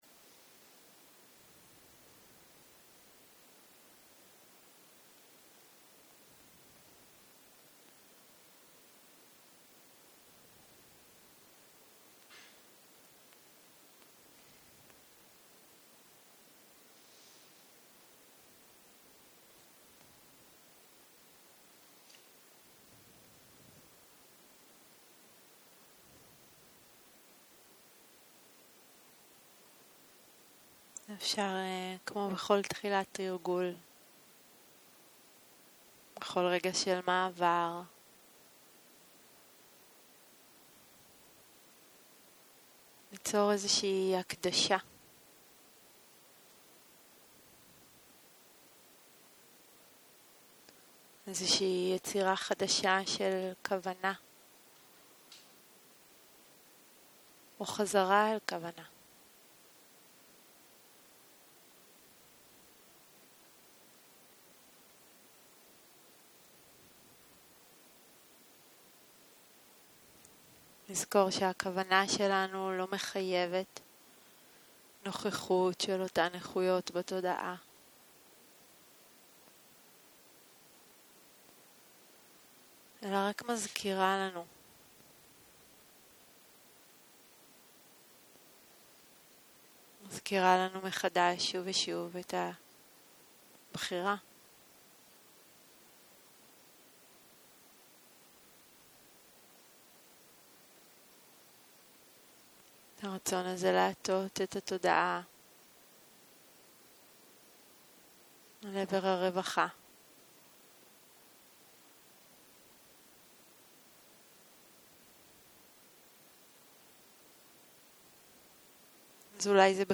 יום 4 - צהרים - מדיטציה מונחית - קשב פתוח, צלילים ומצבי תודעה - הקלטה 9